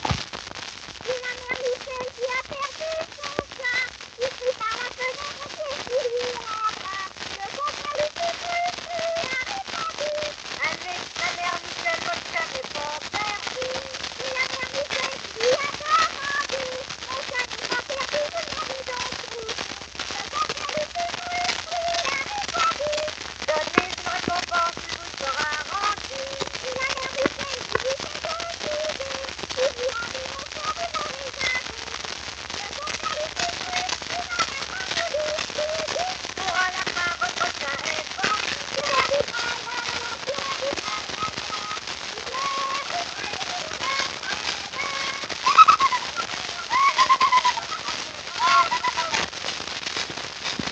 Unbekannte Kinder- und Frauenstimme: Ma mère Michel No. 1.